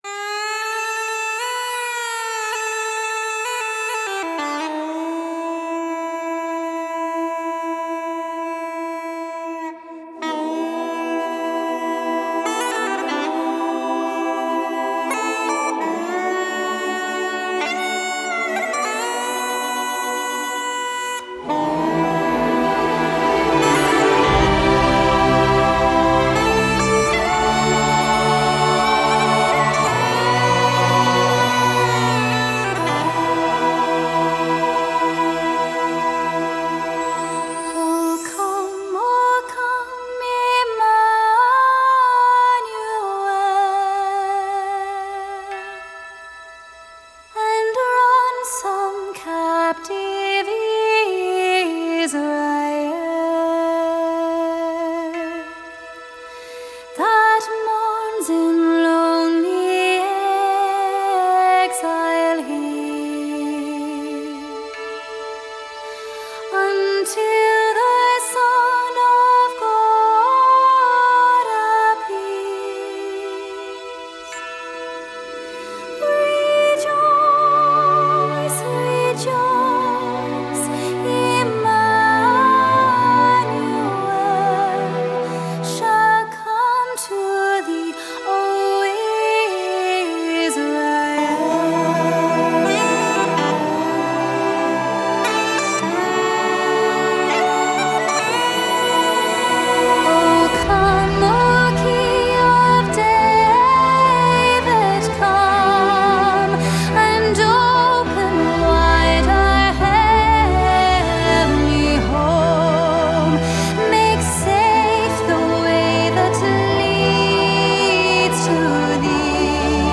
US • Genre: Celtic